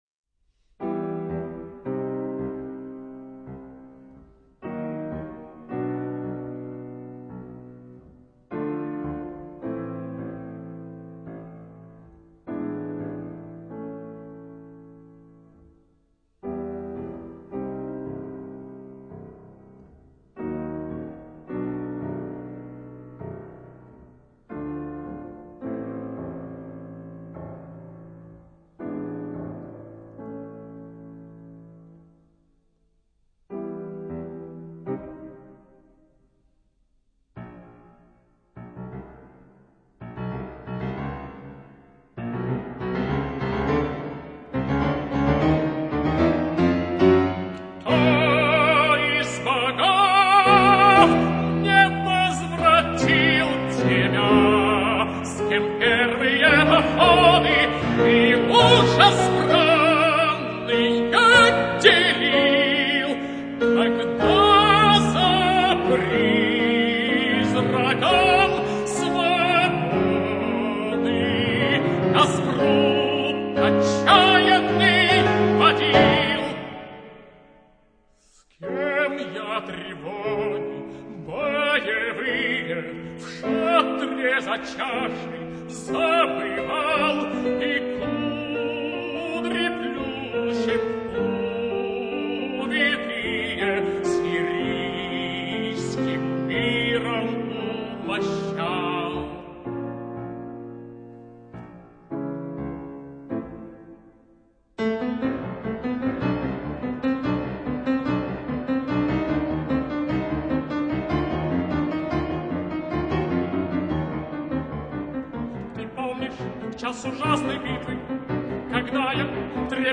tenor
piano